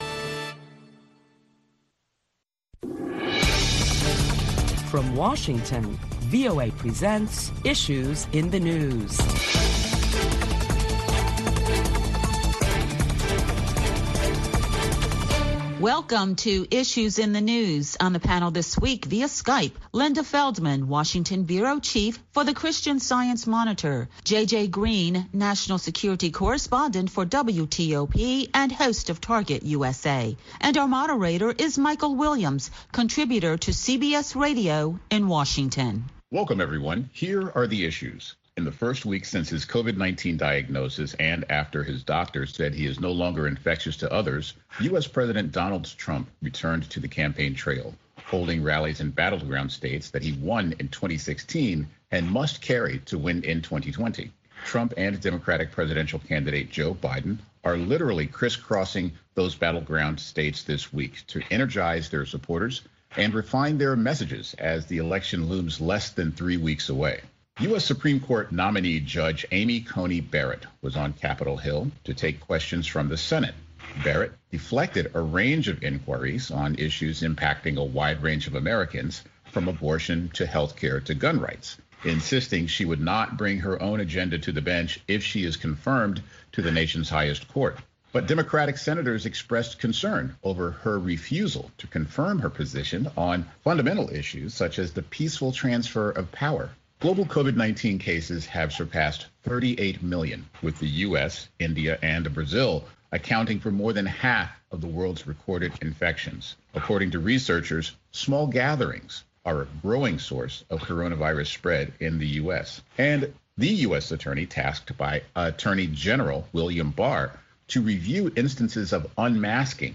A panel of prominent Washington journalists deliberate the latest top stories of the week which, include President Trump and Democratic presidential candidate Joe Biden crisscross battleground states to energize their supporters.